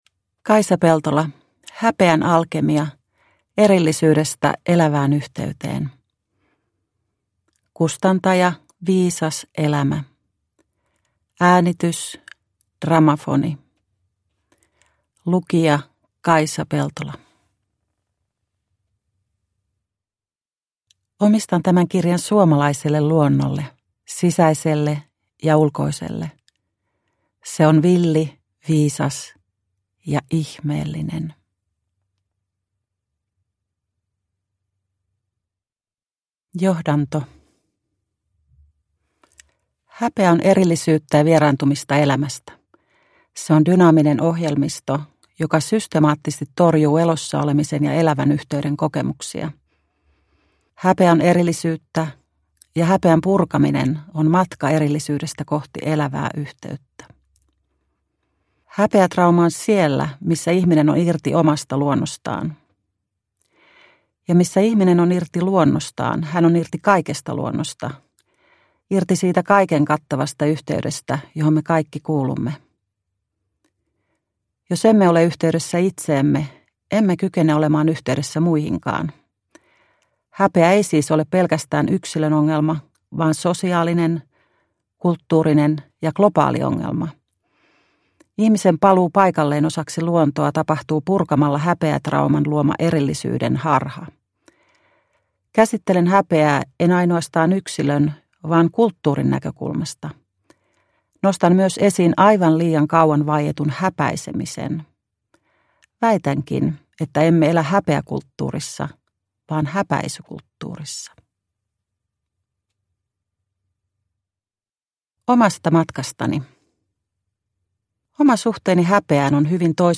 Häpeän alkemia – Ljudbok – Laddas ner